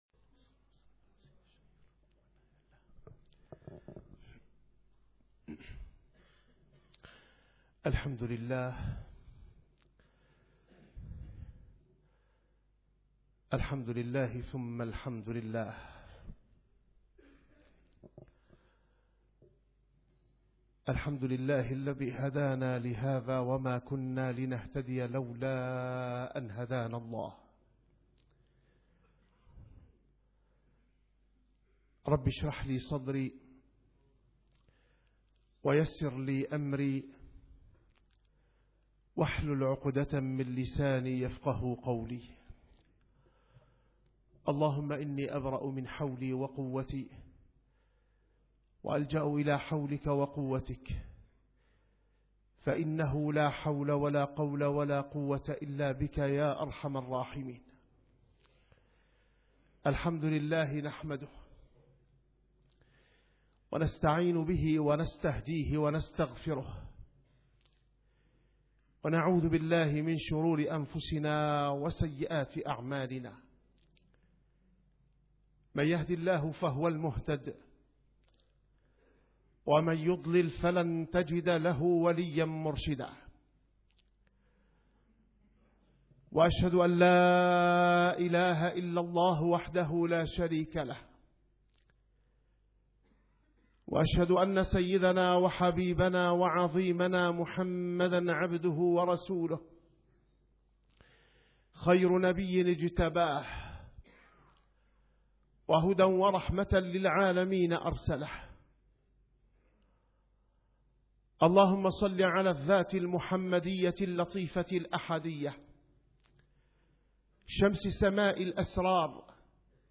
- الخطب - ربط بين دعاء الطائف وحال أهل غزة